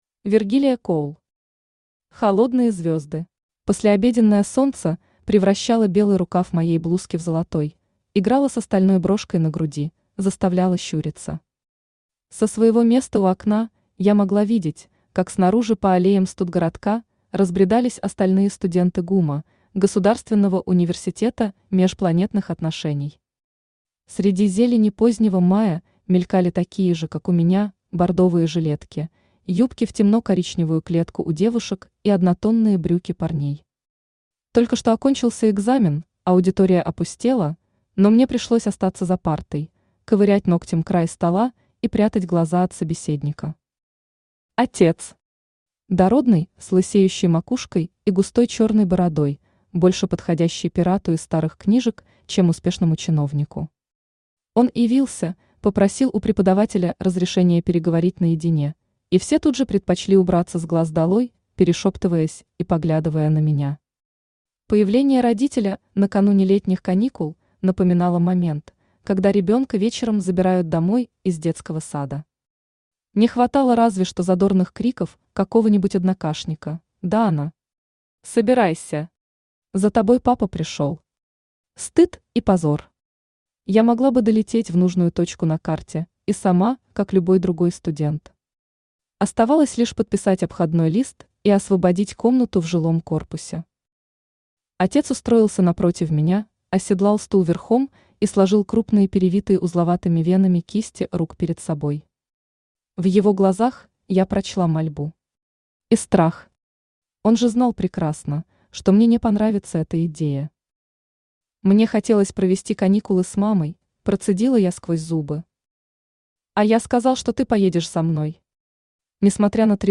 Aудиокнига Холодные звезды Автор Вергилия Коулл Читает аудиокнигу Авточтец ЛитРес.